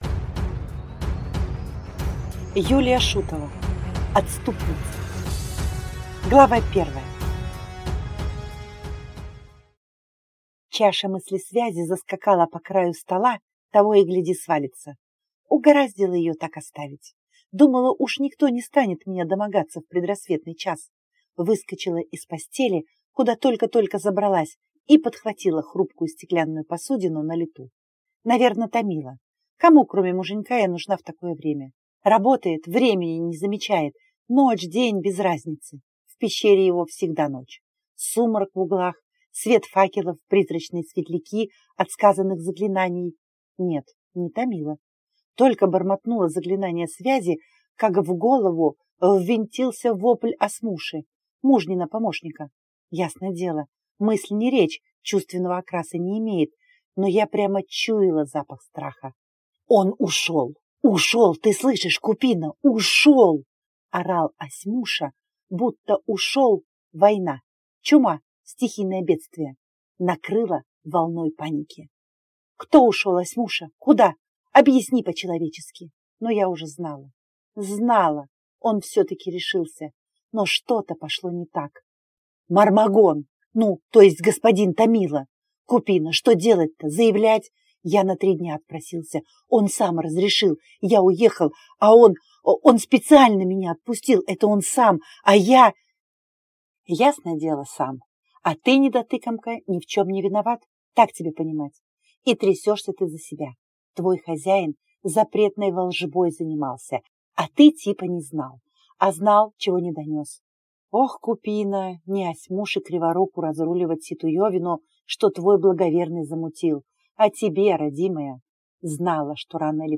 Аудиокнига Отступница | Библиотека аудиокниг